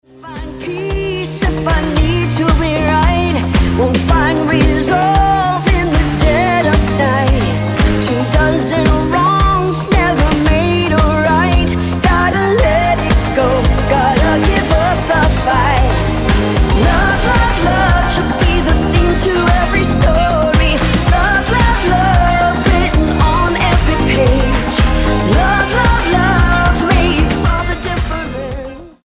Gospel-tinged pop from a US singer
gospel popster